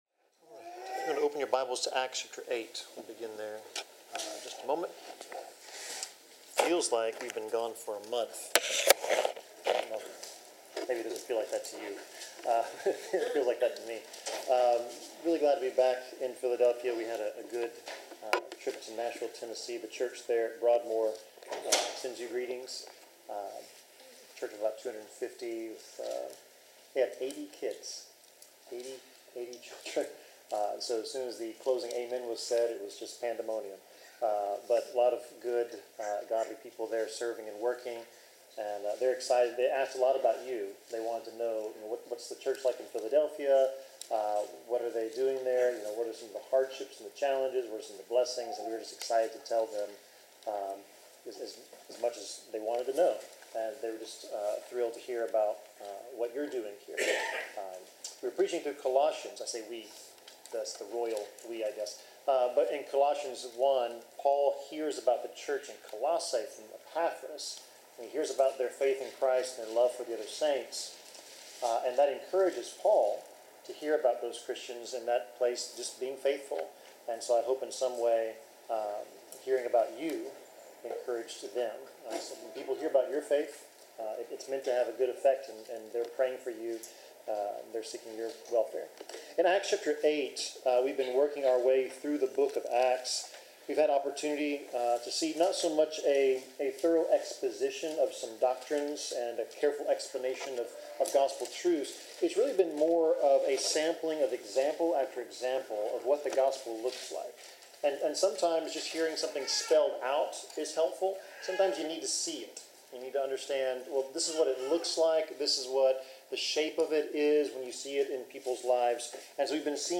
Service Type: Sermon Topics: Baptism , Evangelism , Faith , Jesus , Obedience , Unity